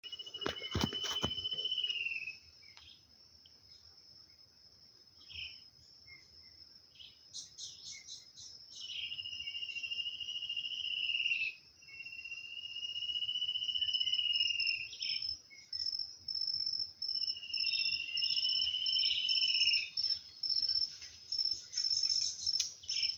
Chocão-carijó (Hypoedaleus guttatus)
Nome em Inglês: Spot-backed Antshrike
Localidade ou área protegida: Parque Provincial Teyú Cuaré
Condição: Selvagem
Certeza: Gravado Vocal